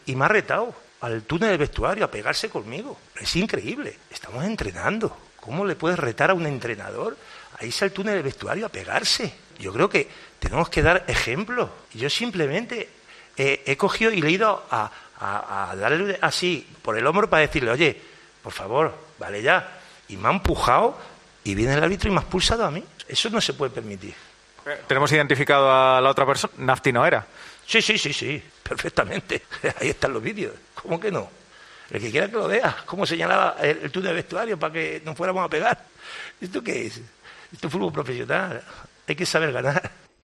AUDIO: El entrenador del Fuenlabrada denunció en rueda de prensa las amenazas del entrenador del Leganés tras perder el partido 3-2.